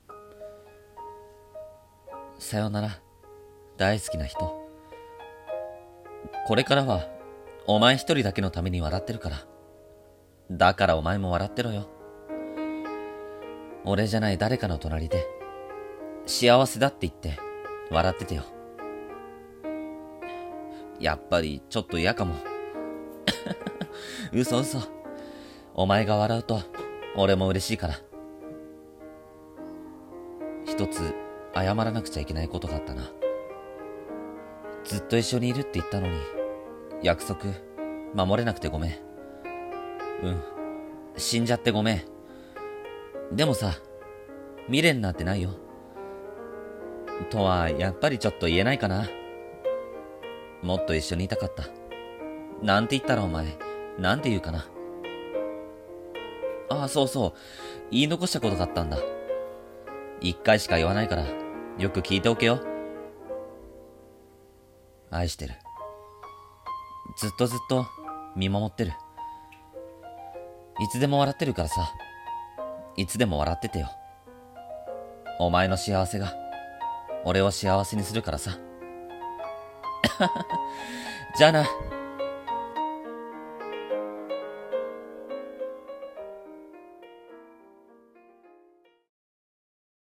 声劇「最期のメッセージ」